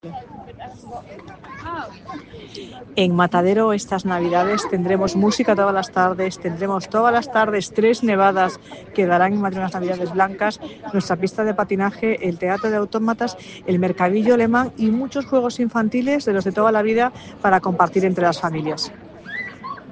Nueva ventana:Declaraciones de la delegada de Cultura, Turismo y Deporte, Marta Rivera de la Cruz en la inauguración de ‘Madrid Navidad de Encuentro’